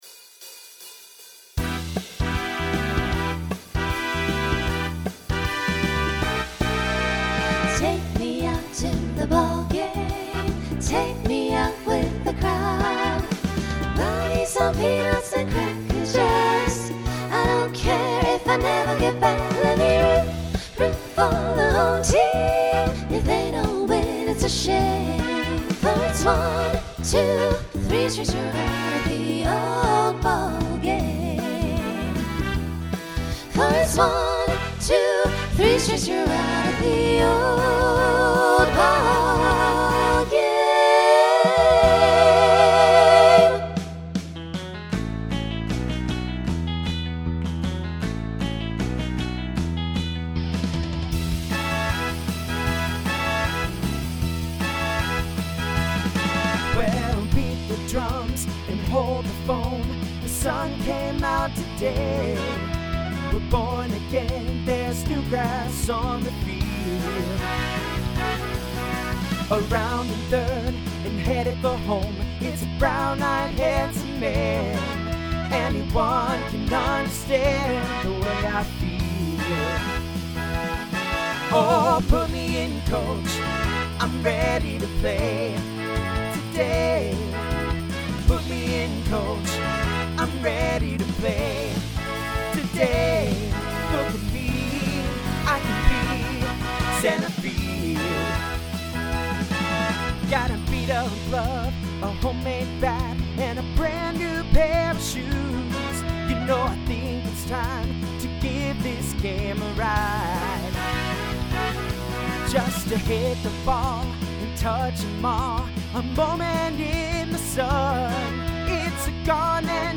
(SSA trio)
(TTB)
Genre Rock , Swing/Jazz
Transition Voicing Mixed